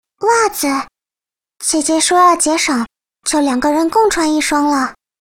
贡献 ） 协议：Copyright，人物： 碧蓝航线:平海语音 2020年8月13日